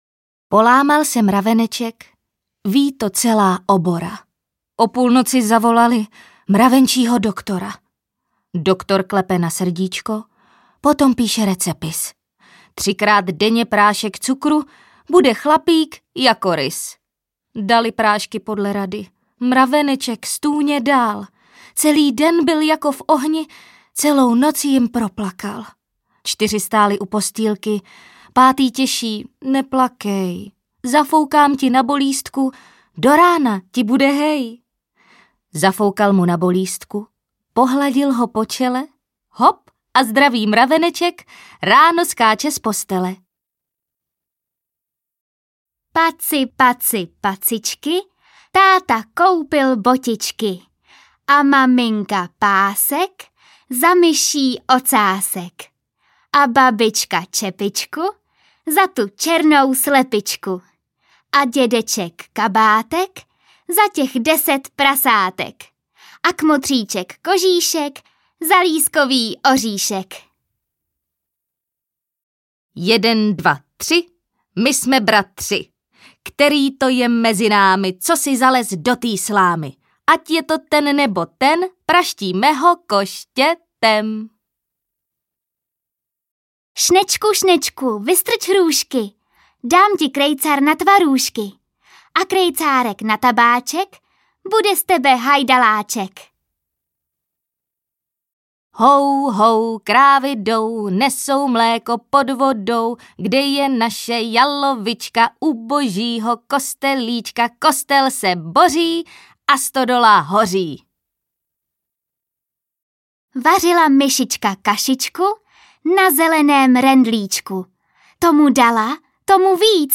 Česká říkadla, popěvky, rozpočítadla a básničky pro nejmenší posluchače a jejich rodiče Polámal se mraveneček, Vařila myšička kašičku, Paci, paci, pacičky a dalších více než 300 veršovánek! První rýmy a říkačky jsou nejlepším způsobem, jak rozvíjet dětskou řeč od batolecího věku.